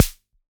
RDM_TapeB_SR88-Snr.wav